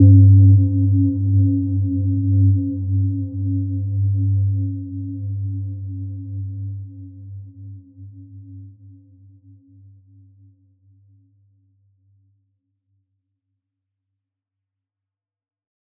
Gentle-Metallic-4-G2-f.wav